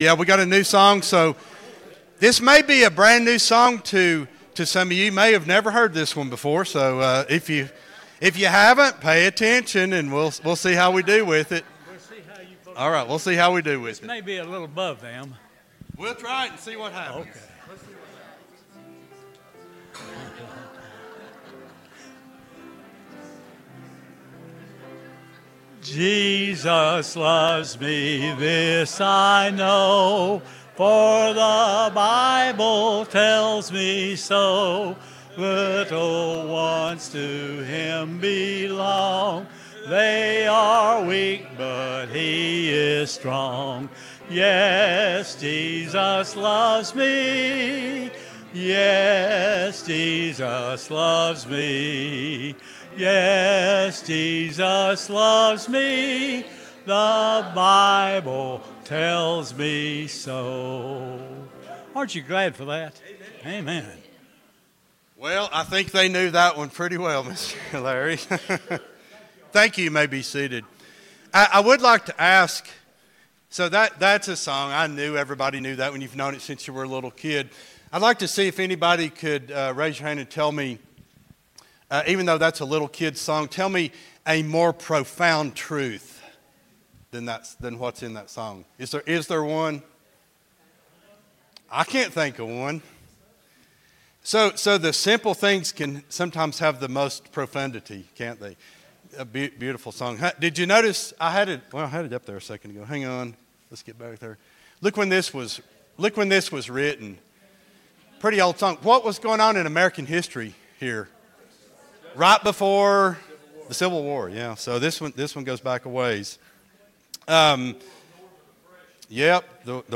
09-07-25 Sunday School Lesson | Buffalo Ridge Baptist Church